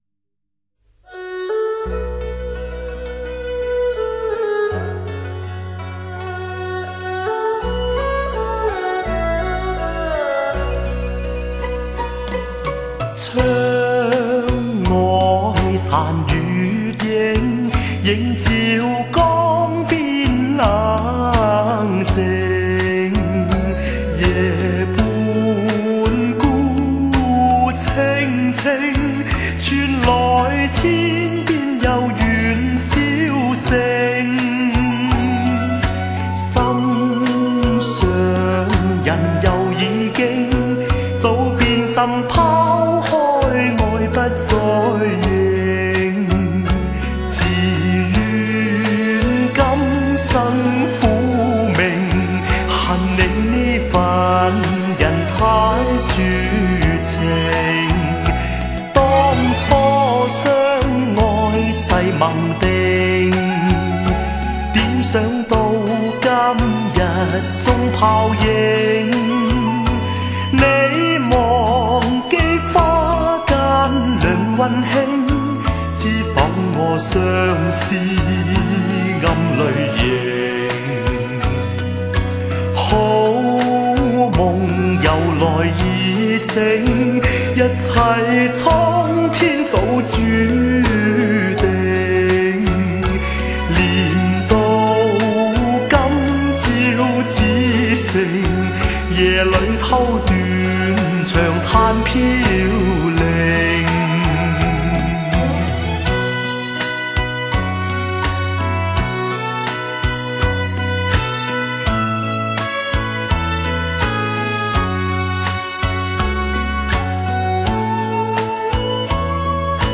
广东粤曲清新柔丽，是中国曲艺家族中的重要成员。
它用广州方言演唱，流行于广东、广西、香港、澳门等广州方言区域，